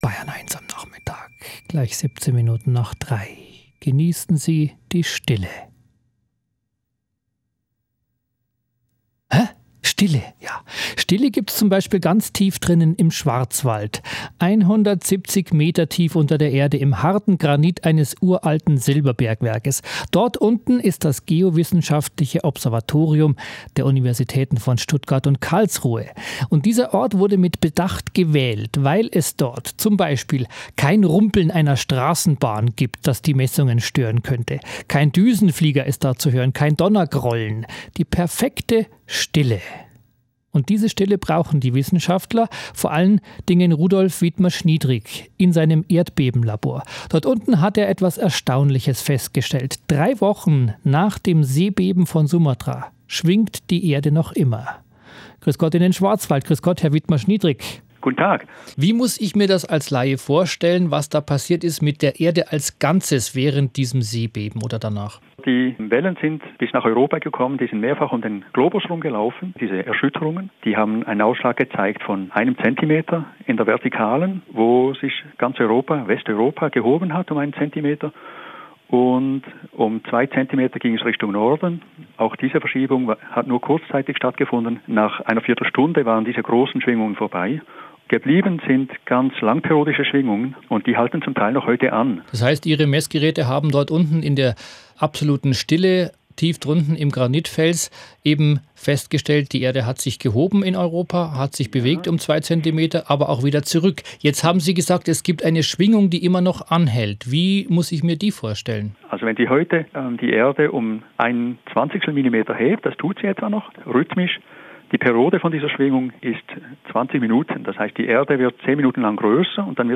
Im Rundfunk...
B1-Cafe-Interview.aiff